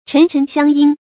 chén chén xiāng yīn
陈陈相因发音
成语正音相，不能读作“xiànɡ”。